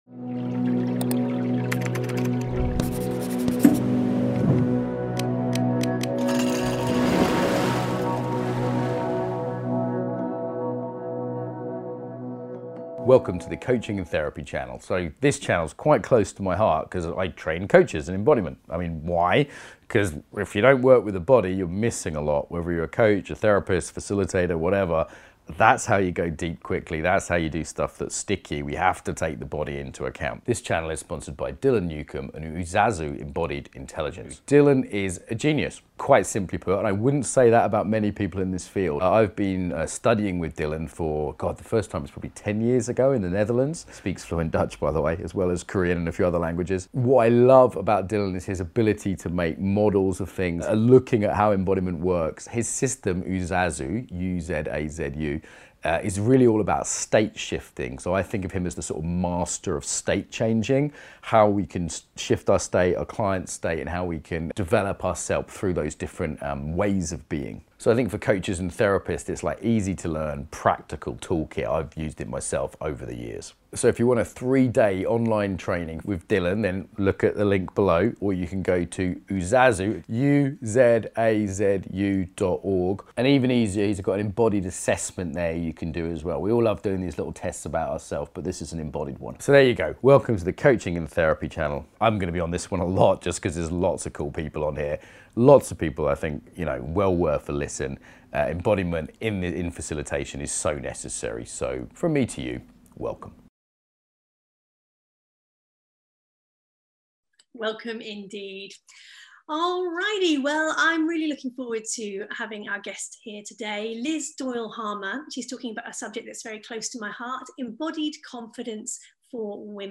Embodied Confidence for Women Beginning or no understanding of topic, Open to all Movement not required Likely soothing No matter how much they accomplish, many women feel as though they are still not good enough.
Guided Practices